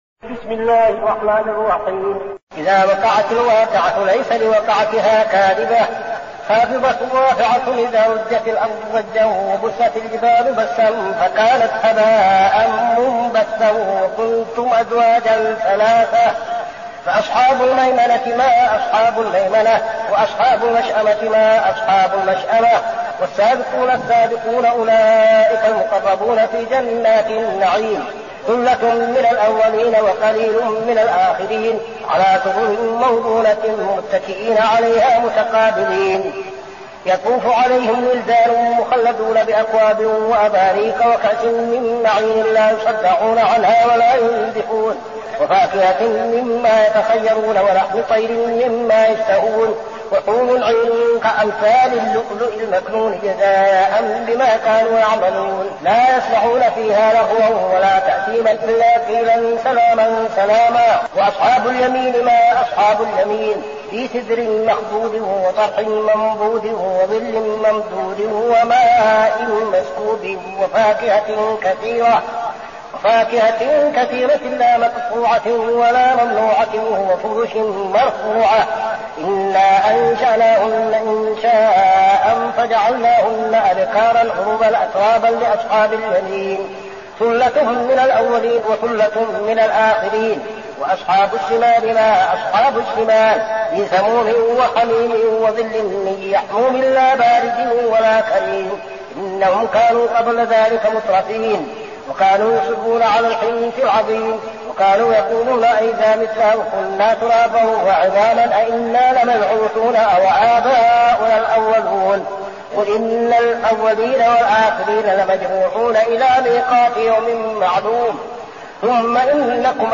المكان: المسجد النبوي الشيخ: فضيلة الشيخ عبدالعزيز بن صالح فضيلة الشيخ عبدالعزيز بن صالح الواقعة